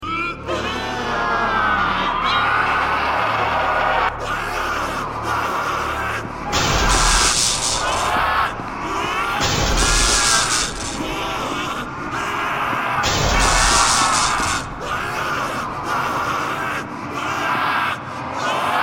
SCP 096 rage destroy doors
scp-096-rage-destroy-doors.mp3